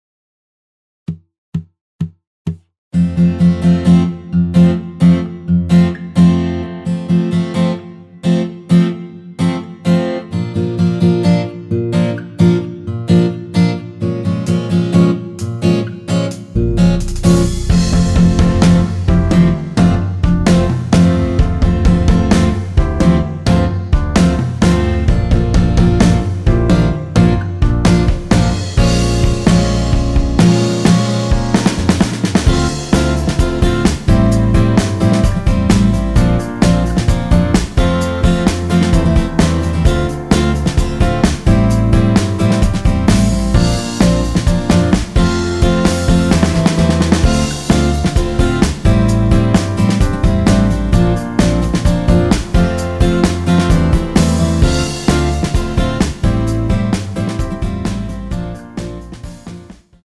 전주 없이 시작하는 곡이라 4박 카운트 넣어 놓았습니다.(미리듣기 확인)
앞부분30초, 뒷부분30초씩 편집해서 올려 드리고 있습니다.
중간에 음이 끈어지고 다시 나오는 이유는